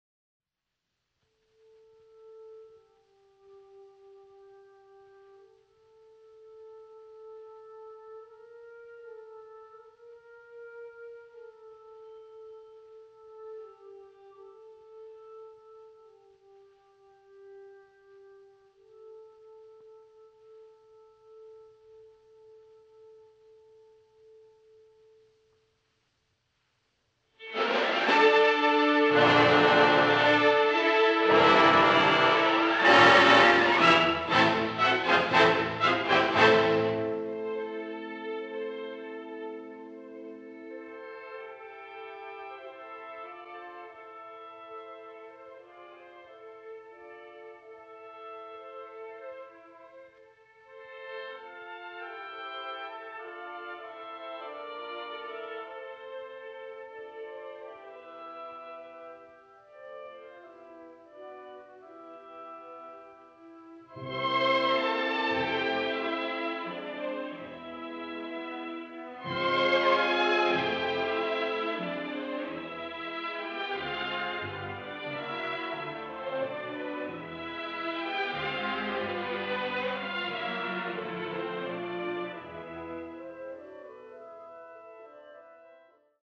Рахманинов — Симфония №3, 1ч, вступление и гл.т..mp3